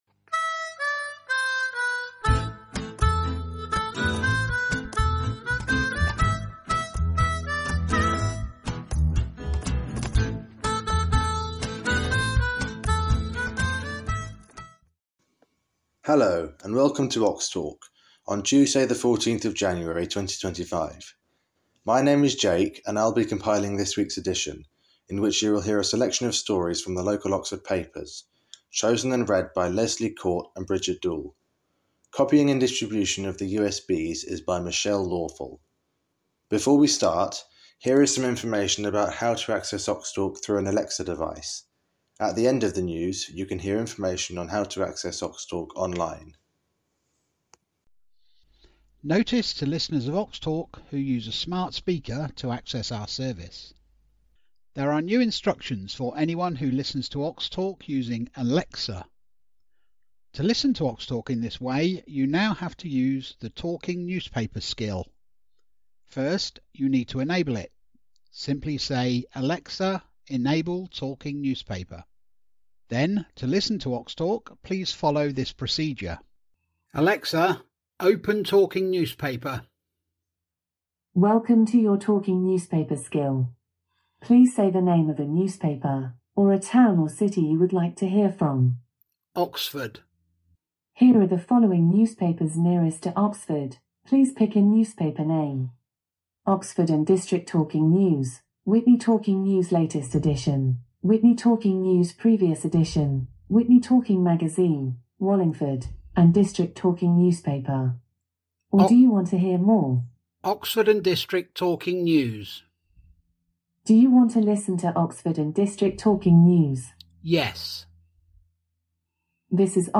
13th January 2025 edition - Oxtalk - Talking newspapers for blind and visually impaired people in Oxford & district